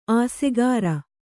♪ āsegāra